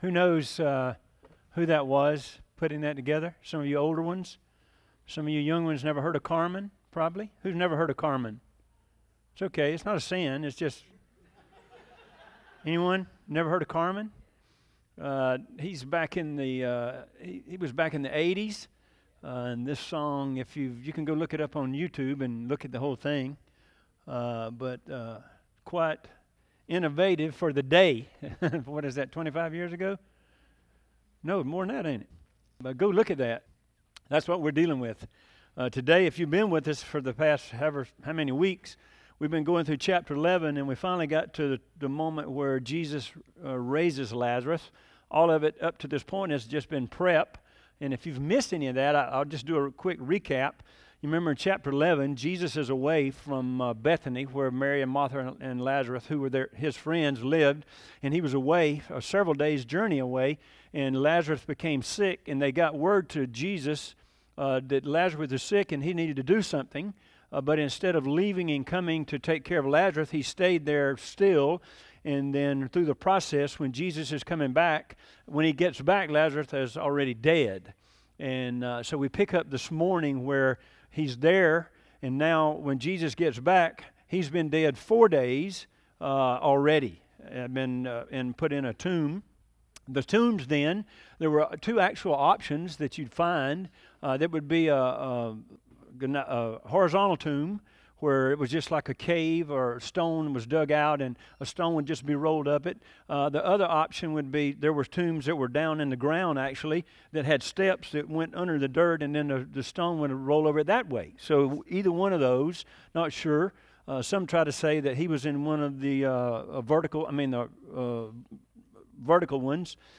0813Sermon.mp3